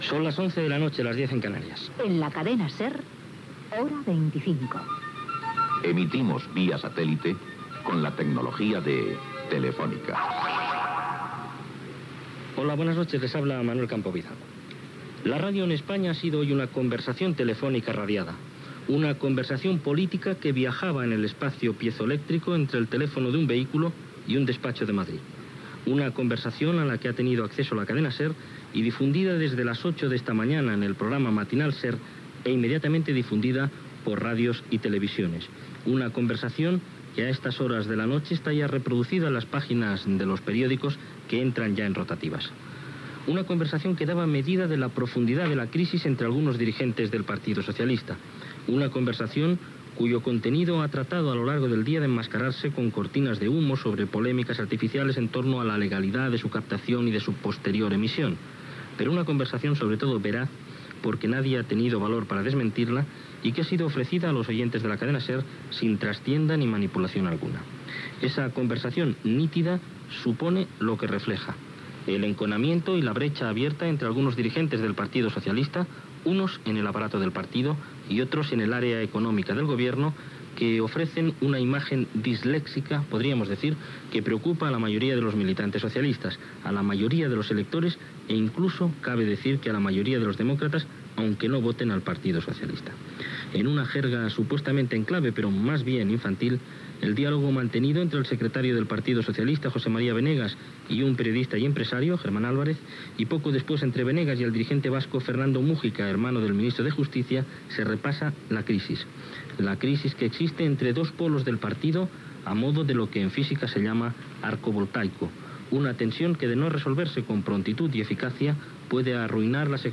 Hora, indicatiu del programa, comentari sobre el "Benegas gate" dins del Partido Socialista Obrero Español (PSOE), reaccions i trobada entre el president del govern espanyol Felipe González i el vice-secretari General del PSOE Alfonso Guerra, sumari informatiu
Informatiu